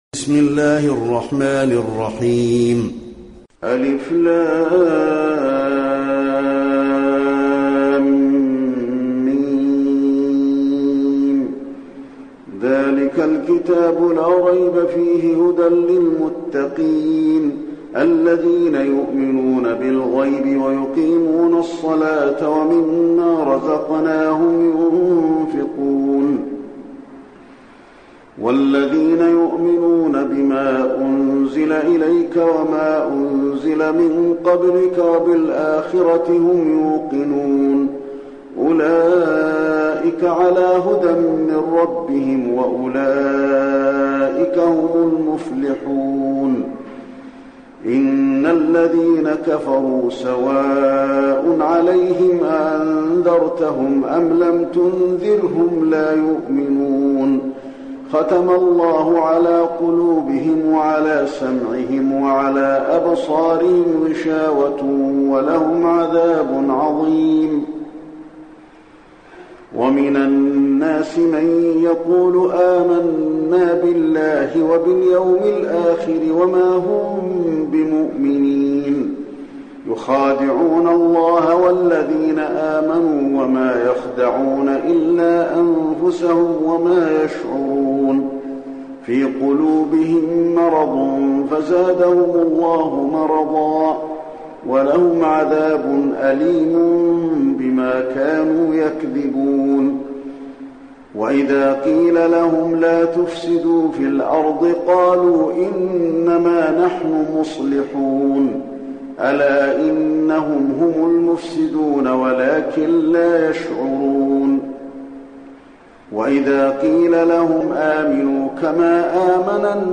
المكان: المسجد النبوي البقرة